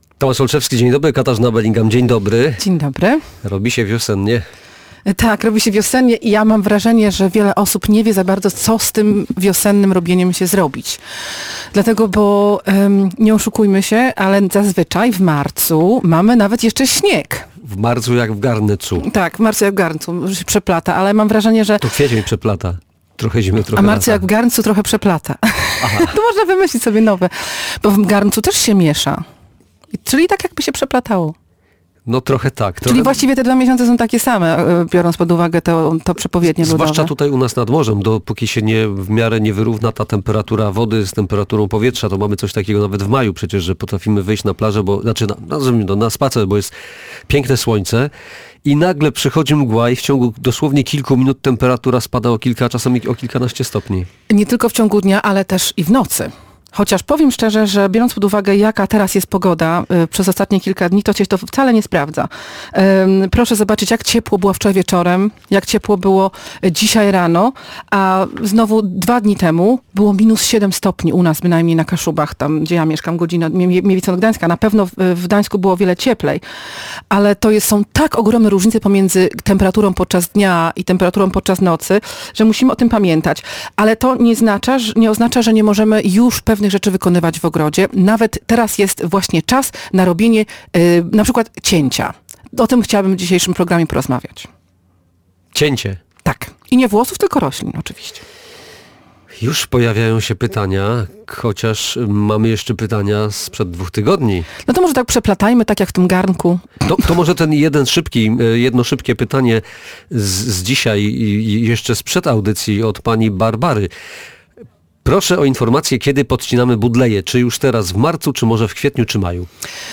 Właśnie teraz jest czas na robienie np. cięcia – powiedziała na antenie Radia Gdańsk specjalistka od ogrodów.